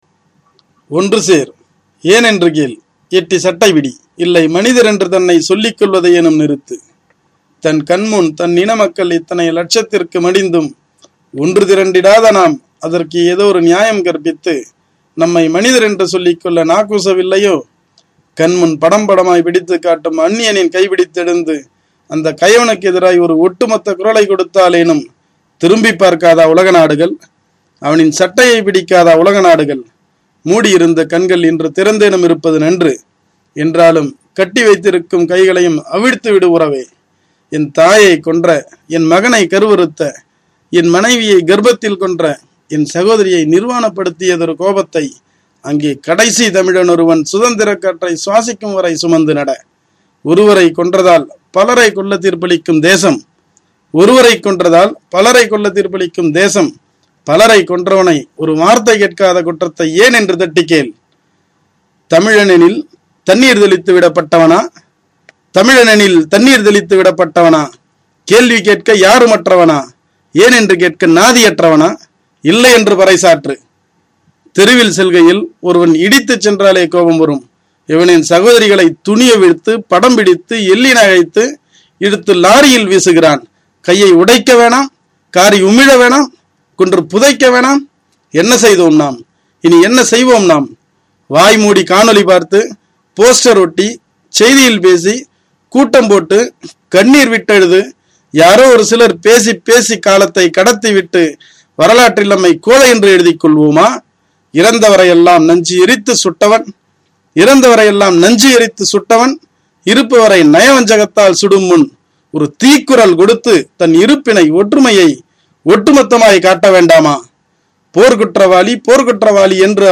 உங்கள் குரல் வளத்தோடு ஒலிக்கும் இக்கவிதை மேலும் சிறப்பாகிறது!!!
எனக்கும் குரலொலி பதிவு போதுமானதாக இல்லை. இது சற்று உணர்வுகளை பகிர்ந்துக் கொள்ள வேண்டி வீட்டிலிருந்த வெப்கேமில் பதிவு செய்தது.